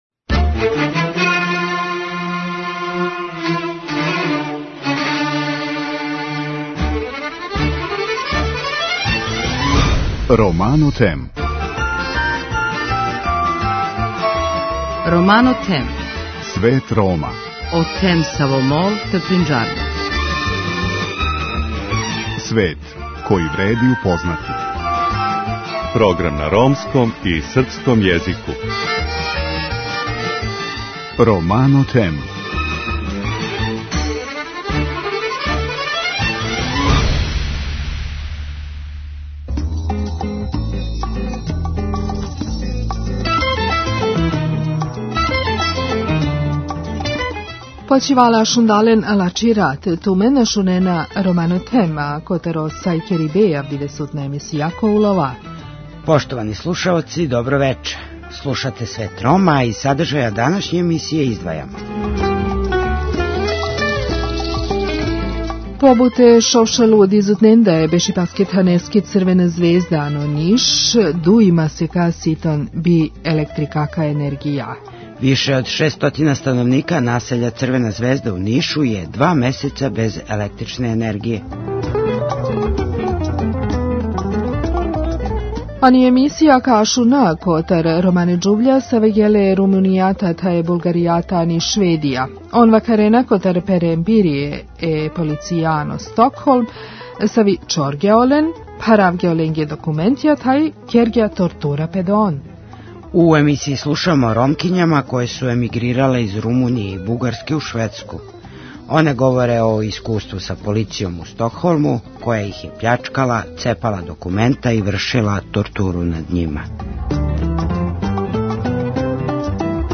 У емисији слушамо о Ромкињама које су емигрирале из Румуније и Бугарске у Шведску. Оне говоре о искуству са полицијом у Стокхолму која их је пљачкала, цепала документа и вршила тортуру над њима.